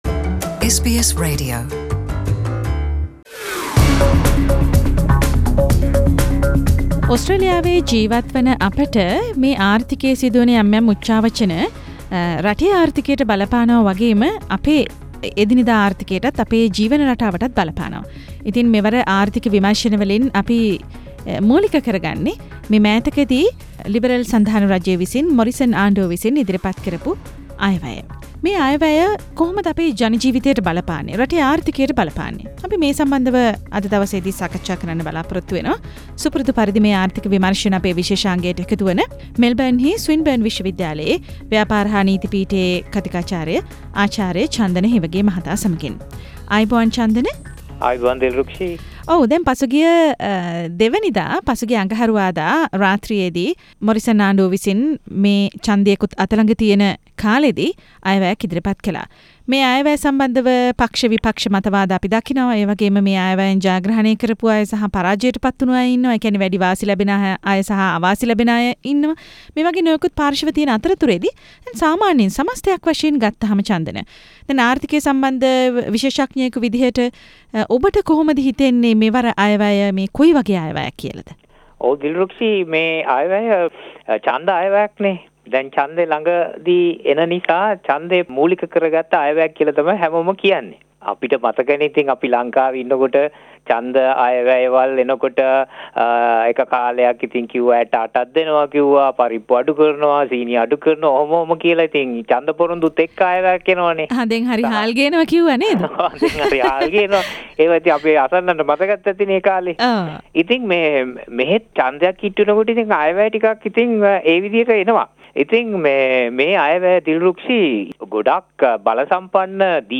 මෙවර ආර්ථික විමර්ශන : වත්මන් ලිබරල සන්ධාන ආණ්ඩුව විසින් පසුගිය අප්‍රේල් 2 දා 2019/2020 මුල්‍ය වර්ෂය සඳහා වන අයවැය ඉදිරිපත් කළා. එහිදී සඳහන් වුනු වැදගත් කාරනා කිහිපයක් පිළිබඳව මෙම සාකච්චාවෙන් අවධානය යොමුකෙරේ.